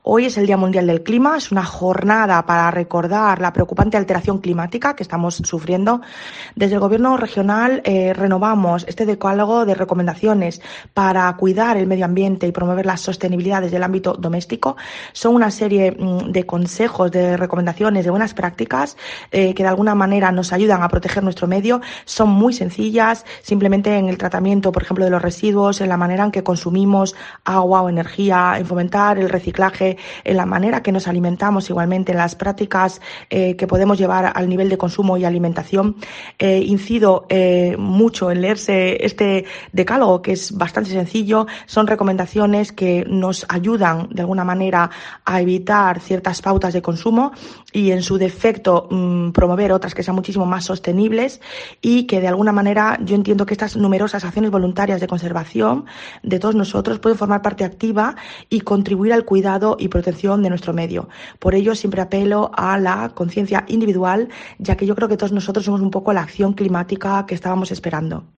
María Cruz Ferreira, secretaria autonómica de Energía, Sostenibilidad y Acción Climática